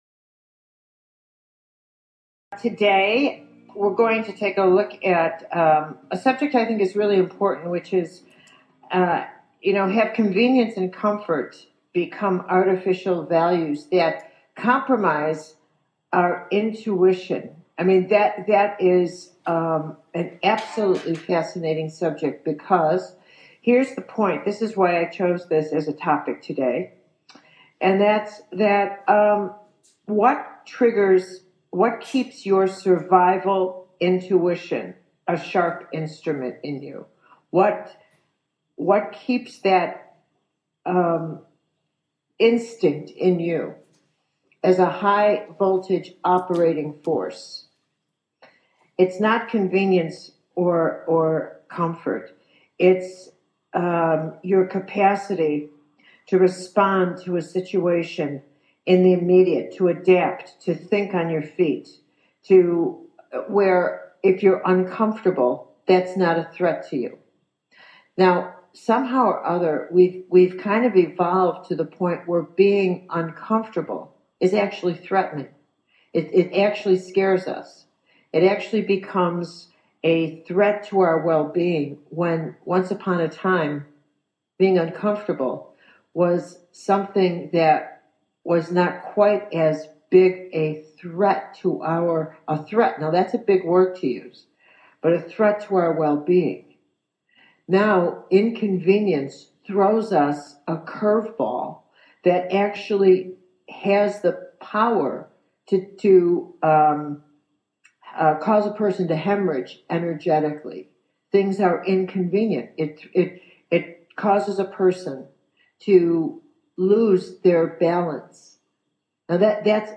A little teaching from Caroline Myss that leads to an important question: How much does my attachment to convenience and comfort compromise intuition ?